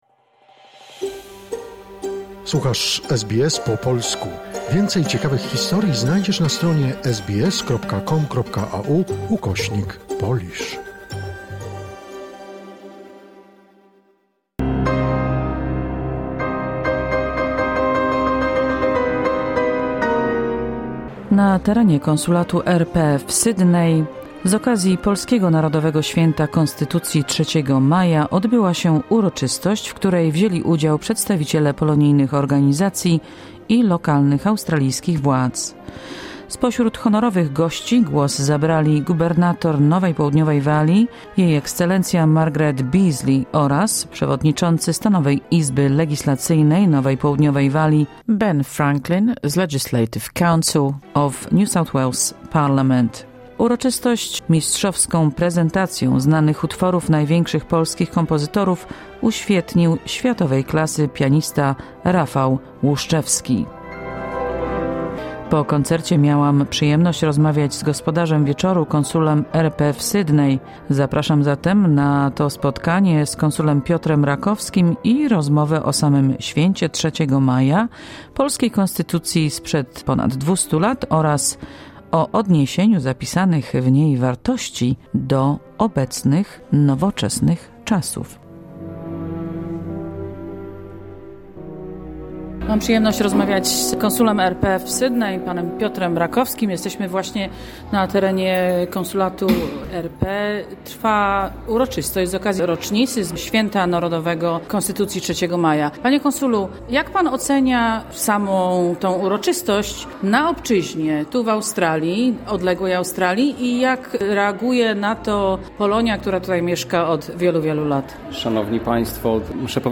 Na terenie Konsulatu RP w Sydney z okazji polskiego Narodowego Święta Konstytucji 3 Maja odbyła się uroczystość, w której wzieli udział przedstawiciele polonijnych organizacji i lokalnych australijskich władz. O Konstytucji 3 Maja mówi Konsul RP w Sydney Piotr Rakowski.